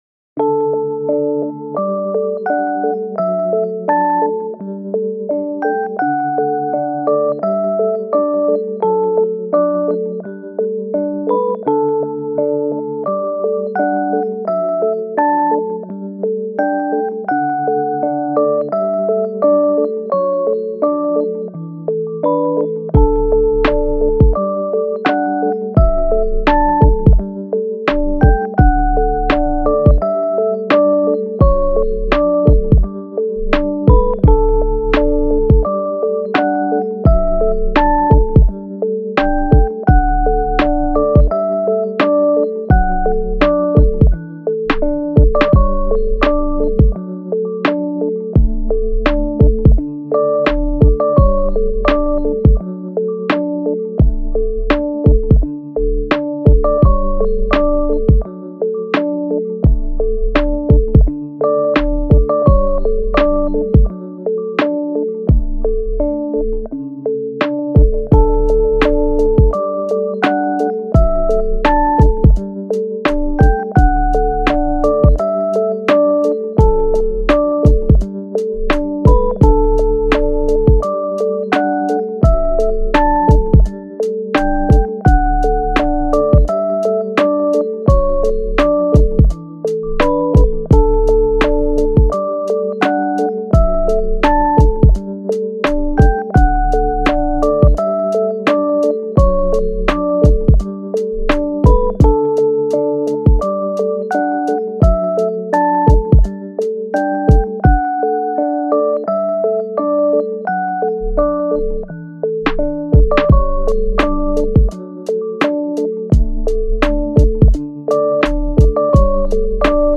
フリーBGM
チル・穏やか